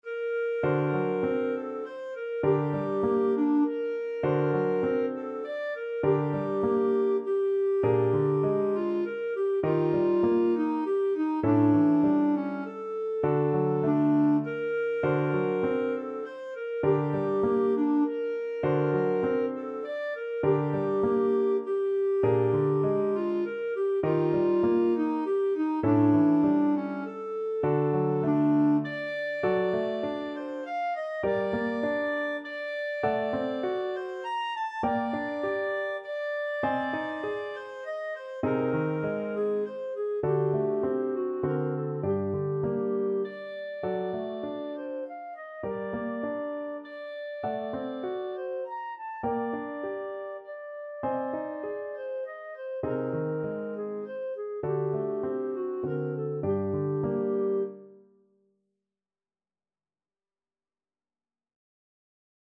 Etwas bewegt
3/4 (View more 3/4 Music)
Classical (View more Classical Clarinet Music)